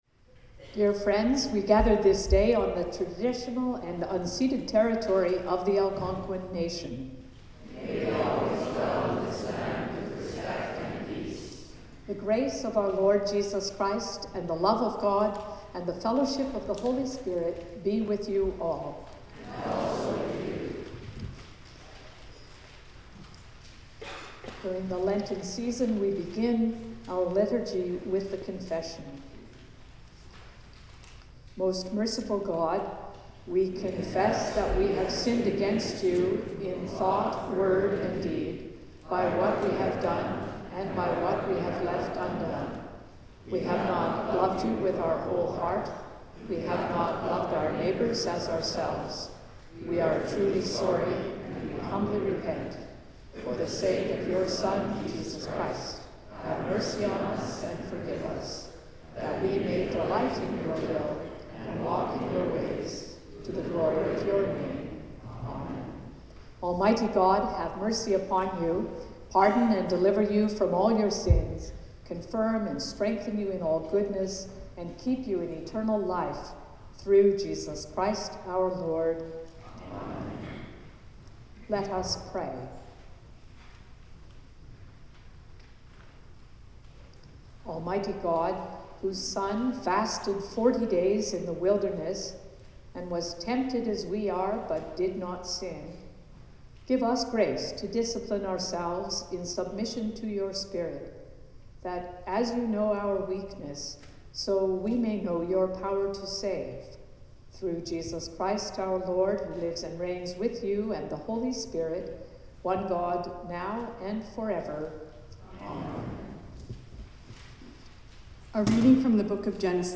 Sermons | St John the Evangelist
Hymn 175: Forty Days and Forty Nights
The Lord’s Prayer (sung)